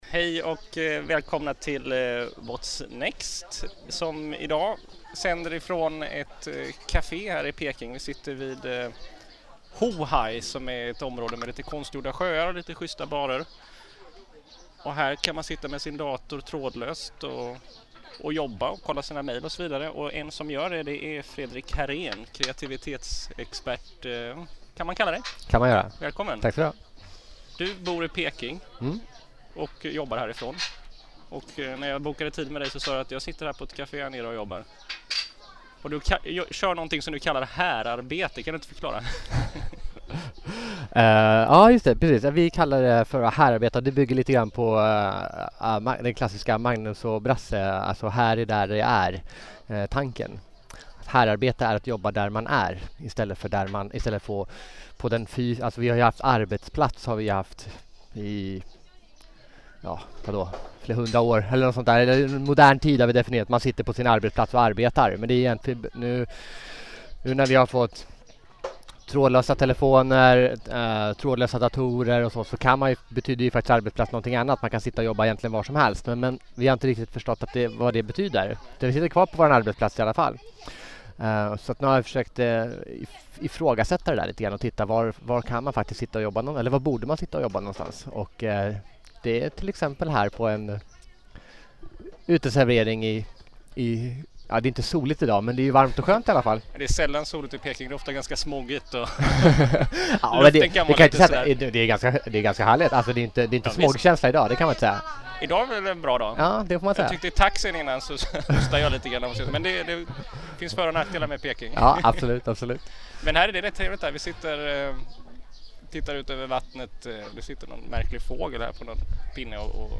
sitter han i bar- och cafédistriktet Hohai i Peking